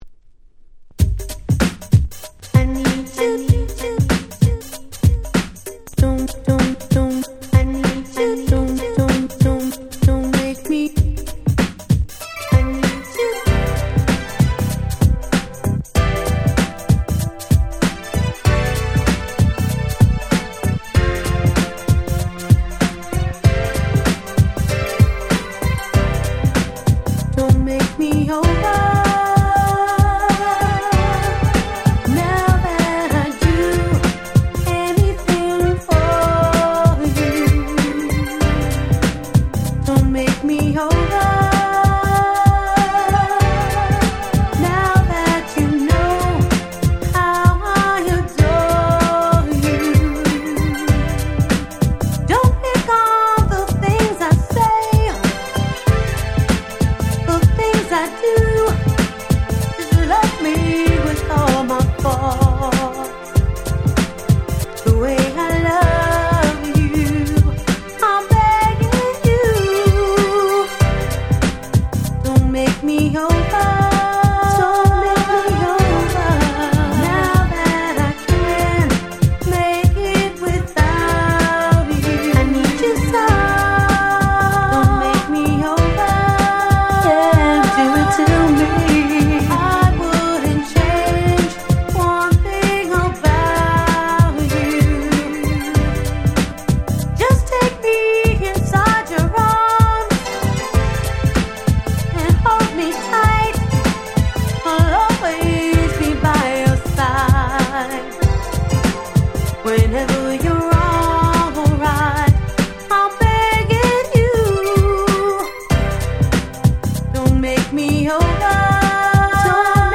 89' Super Hit R&B !!
当時流行っていたGround Beat調のゆったりとしたBeatに綺麗なメロディが最高です！
Grand Beat グラウンドビート キャッチー系